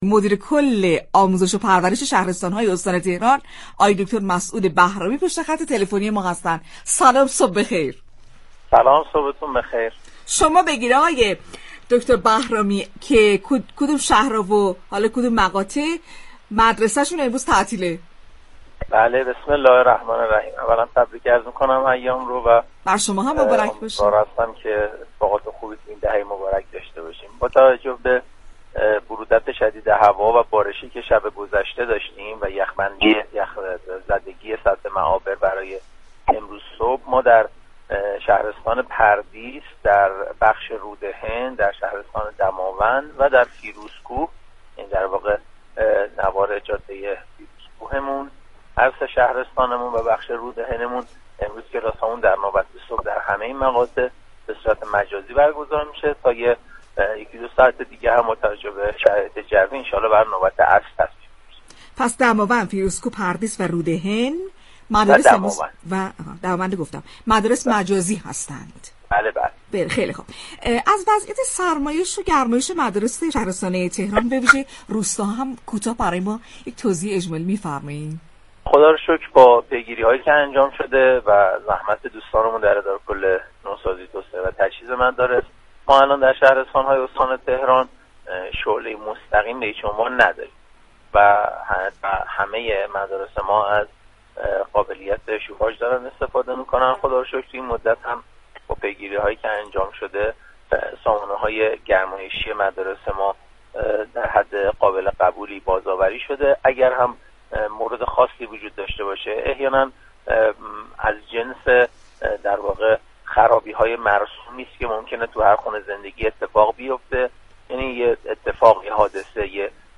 به گزارش پایگاه اطلاع رسانی رادیو تهران، مسعود بهرامی مدیركل آموزش و پرورش شهرستان‌های استان ‌تهران در گفت و گو با «شهر آفتاب» اظهار داشت: به علت بارش برف و برودت هوا و یخ زندگی معابر مدارس شهرستان‌های پردیس، فیروزكوه، دماوند و رودهن تمام مقاطع تحصیلی در نوبت صبح «مجازی» برگزار شد.